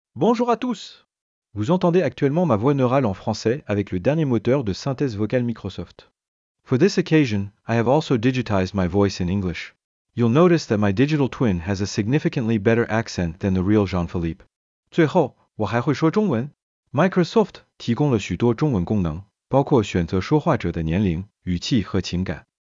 j'avais numérisé ma voix avec les Custom Neuro Voice de Microsoft qui évoluent très très vite (des surprise en cours).